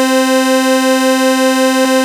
OSCAR 1  C5.wav